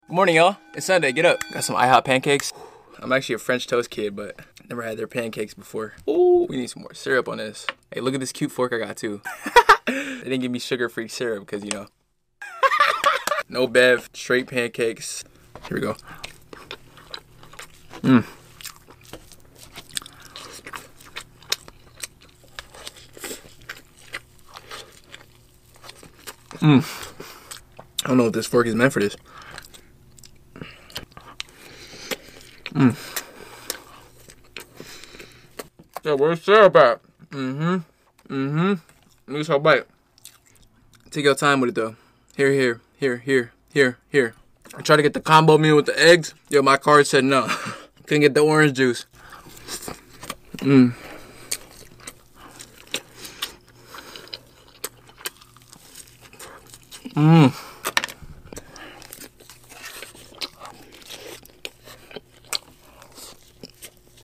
ihop pancake asmr sound effects free download